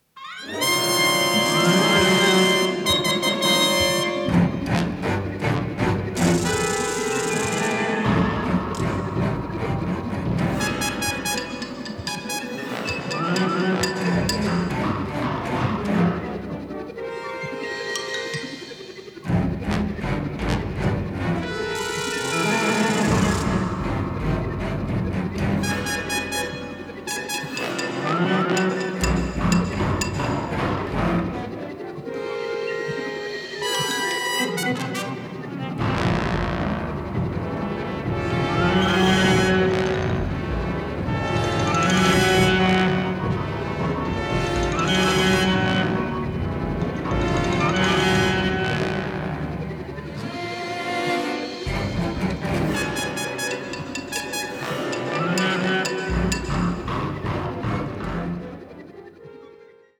Sound quality is excellent.